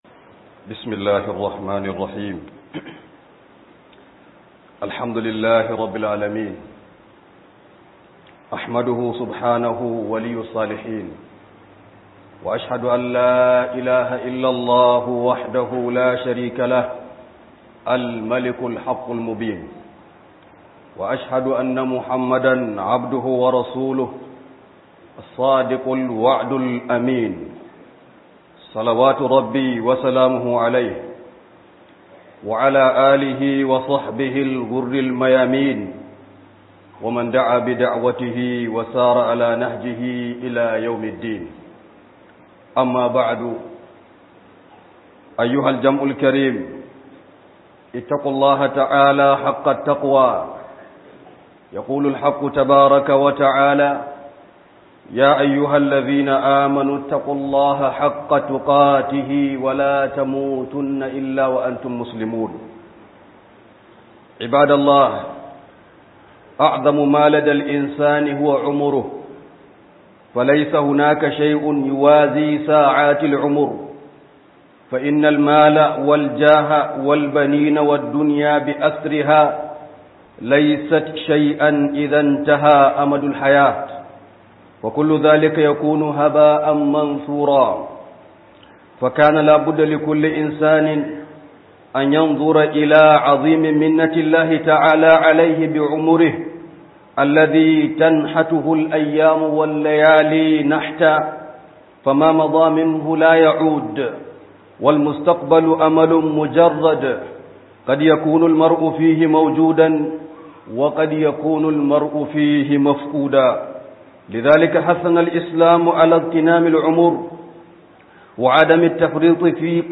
011 AMFANI DA LOKACI - HUDUBA